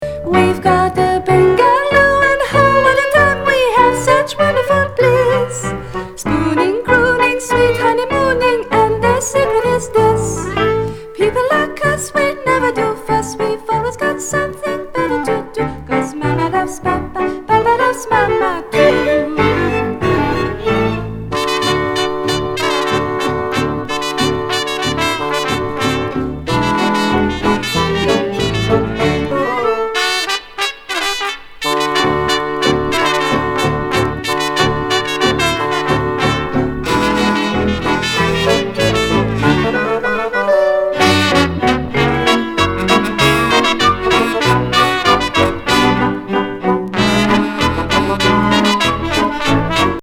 シャンソン、タンゴ、ラグタイム等をレトロ・ムードに演奏。